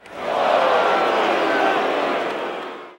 aww.mp3